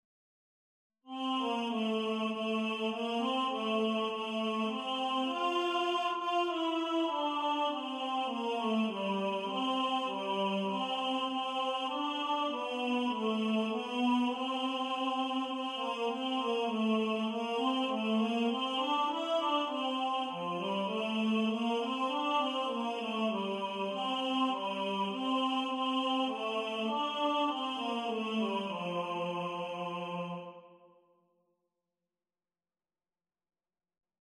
Away-In-A-Manger-Tenor.mp3